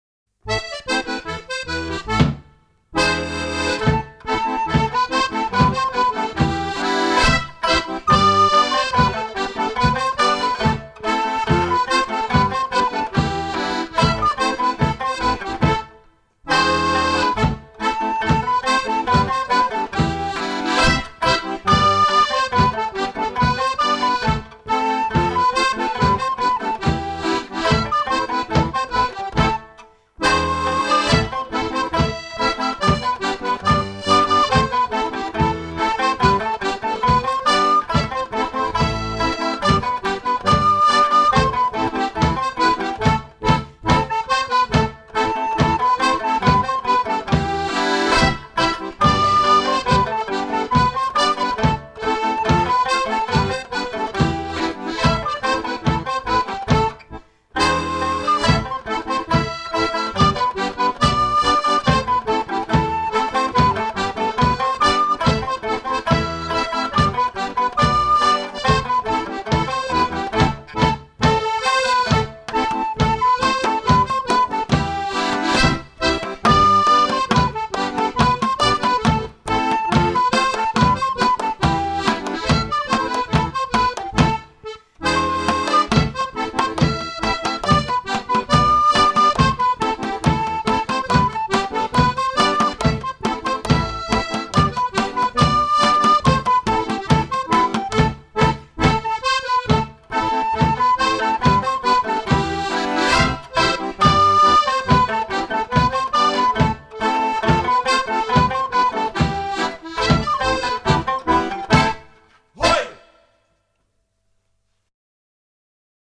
Molly Green Fingers William Taylor's Table-Top Hornpipe,